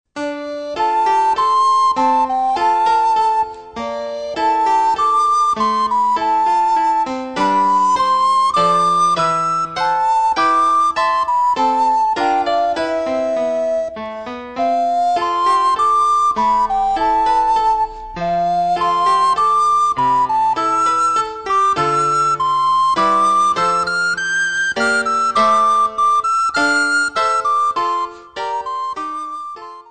für Sopranblockflöte und Klavier
Besetzung: Sopranblockflöte und Klavier
Klassisches Blockflötenrepertoire auf Noten mit Playback-CD.